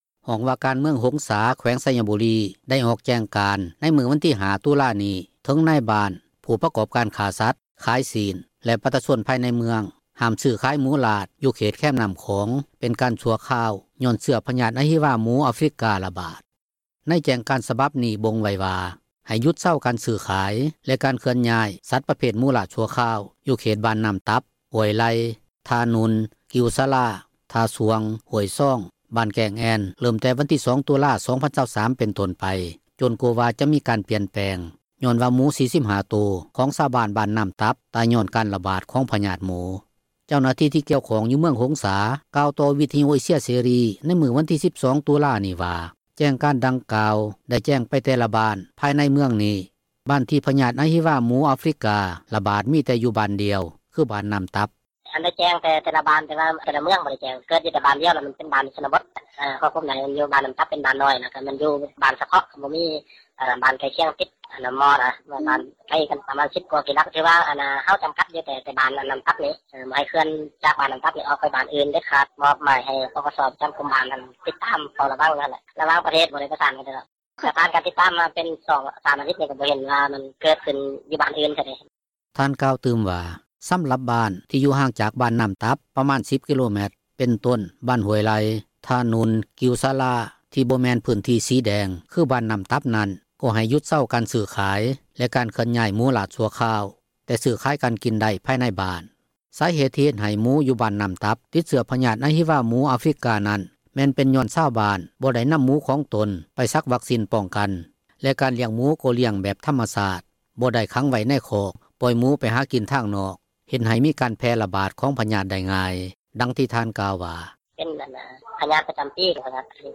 ທາງການສັ່ງຫ້າມ ຊື້-ຂາຍໝູ ຢູ່ເມືອງຫົງສາ ທີ່ຕິດພະຍາດ ອະຫິວາໝູ — ຂ່າວລາວ ວິທຍຸເອເຊັຽເສຣີ ພາສາລາວ
ເຈົ້າໜ້າທີ່ ທີ່ກ່ຽວຂ້ອງ ຢູ່ເມືອງຫົງສາ ກ່າວຕໍ່ວິທຍຸເອເຊັຽເສຣີ ໃນມື້ວັນທີ 12 ຕຸລານີ້ວ່າ ແຈ້ງການດັ່ງກ່າວ ໄດ້ແຈ້ງໄປແຕ່ລະບ້ານ ພາຍໃນເມືອງນີ້, ບ້ານທີ່ພະຍາດອະຫິວາໝູ ອາຟຣິກາ ຣະບາດຢູ່ບ້ານ ບ້ານນໍ້າຕັບ ບ້ານດຽວ.